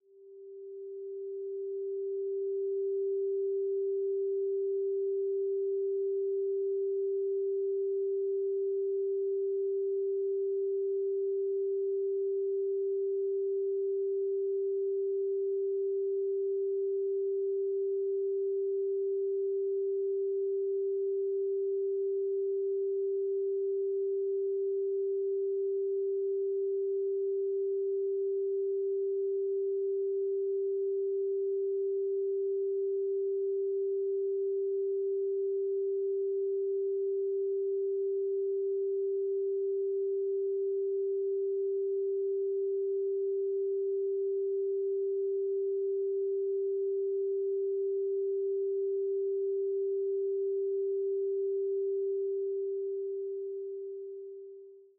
🎹 Classical Piano Collection
Beautiful piano pieces inspired by the great composers.
Duration: 0:55 · Genre: Classical · 128kbps MP3